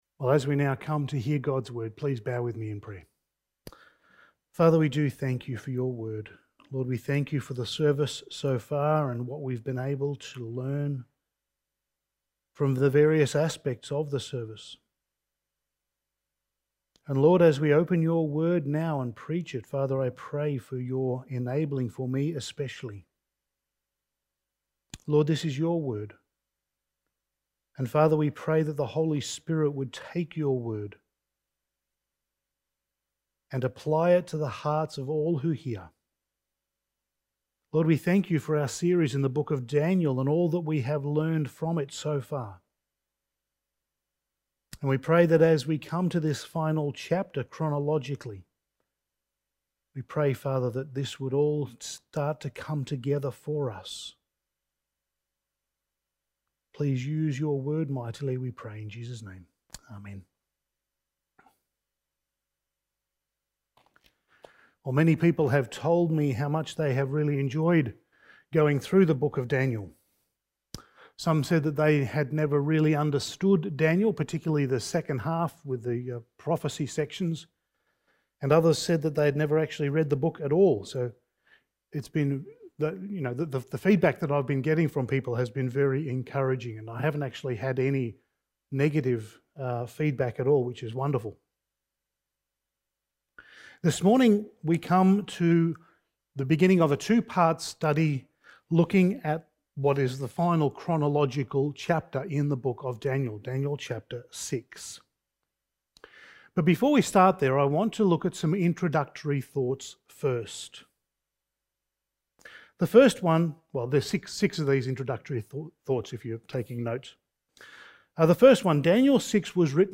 Passage: Daniel 6:1-16 Service Type: Sunday Morning